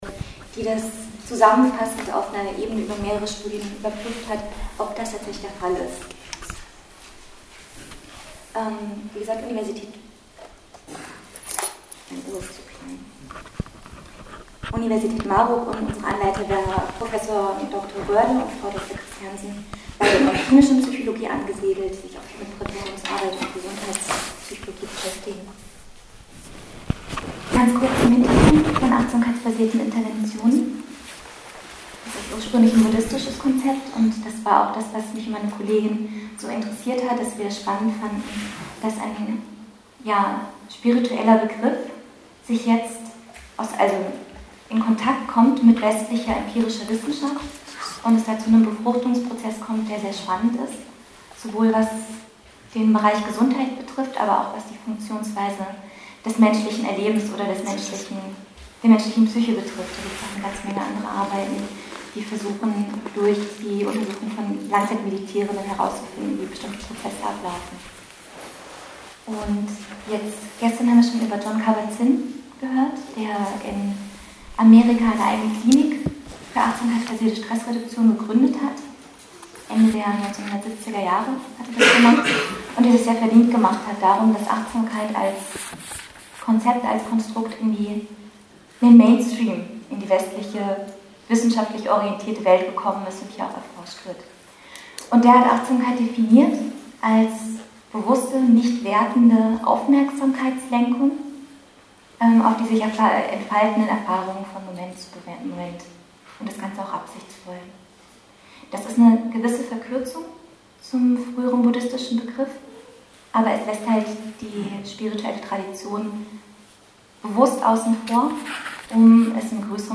Es ist eine Metastudie, welche alle "guten" Studien zum Thema zusammenfasst. Leider hat die Aufnahme am Anfang nicht richtig funktioniert - daher beginnt sie etwas abrupt.